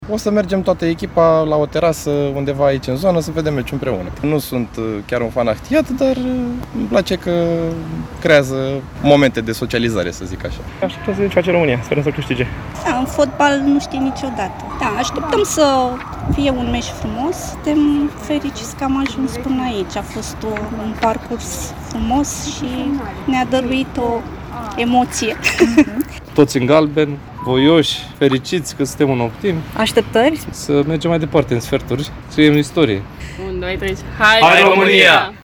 „O să mergem toată echipa la o terasă, undeva aici în zonă să vedem meciul împreună”, spune un bărbat.